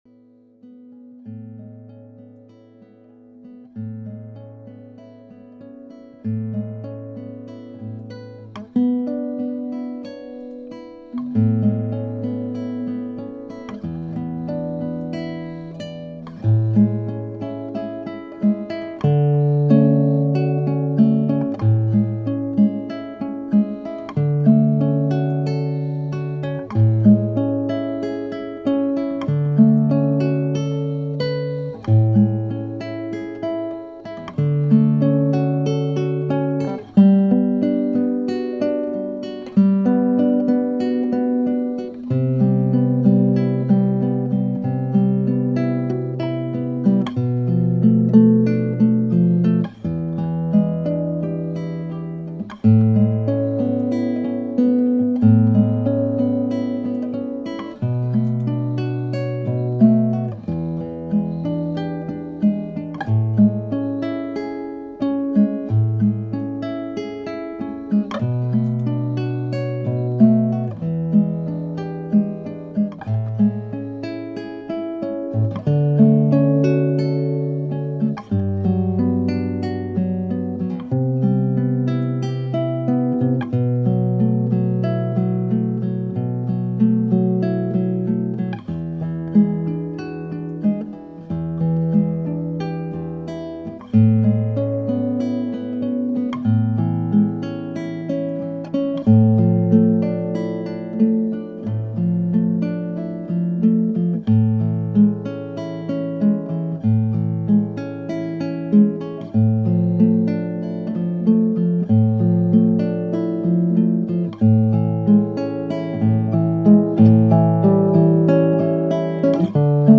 multi-dimensional bilateral sound healing